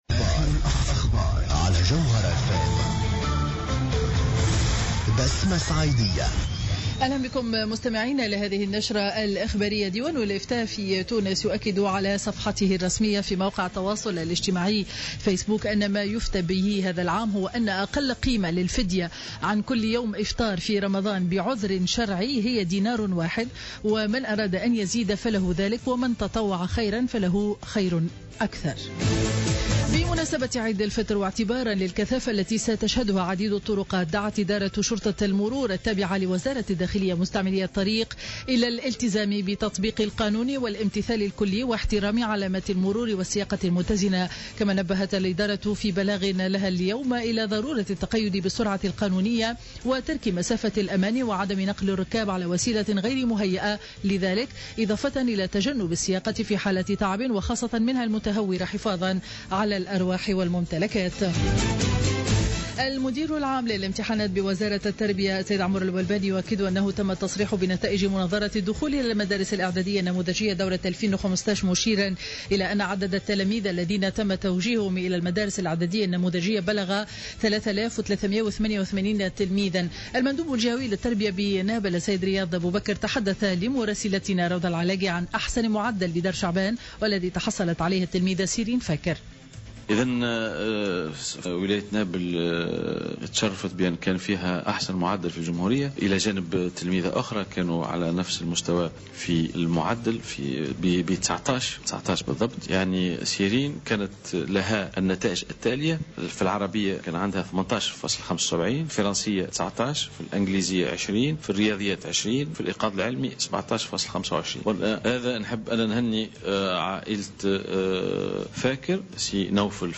نشرة أخبار منتصف النهار ليوم الخميس 16 جويلية 2015